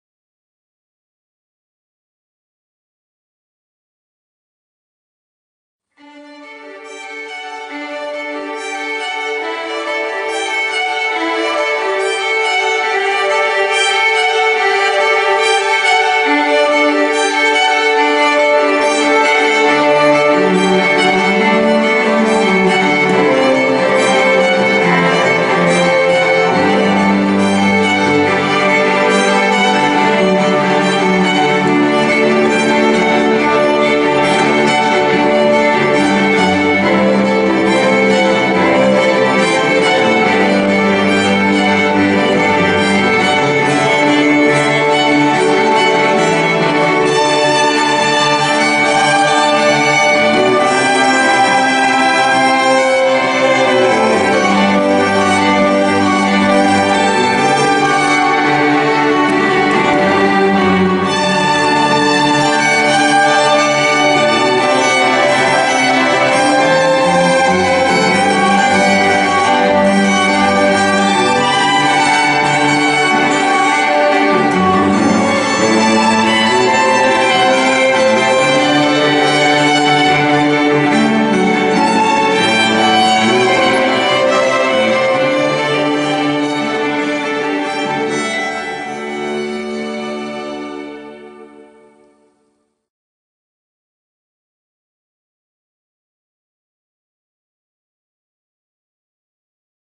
• Multi-award-winning string quartet